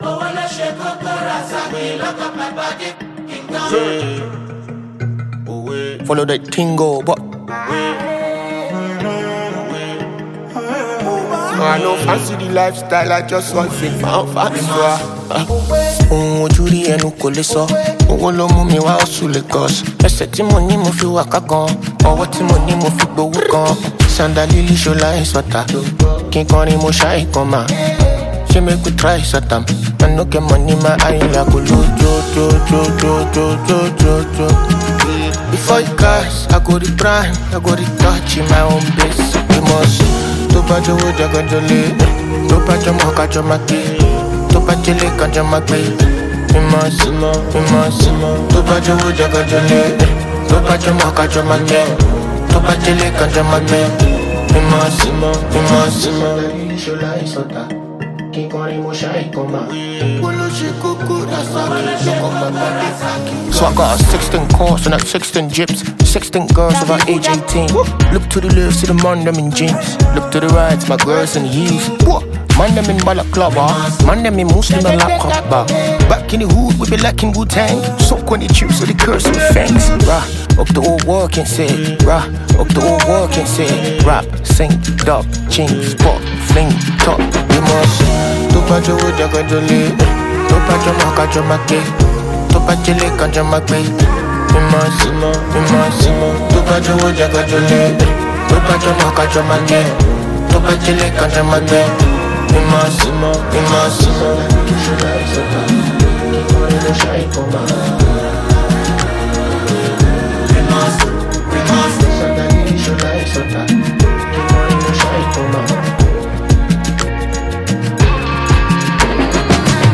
spine-tingling song
is a Nigerian singer and songwriter.